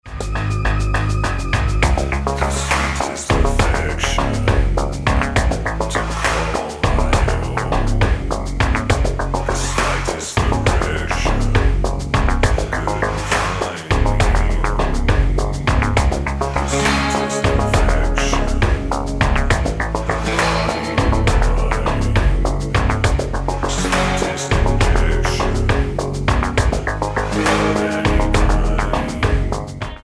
Tonart:C#m mit Chor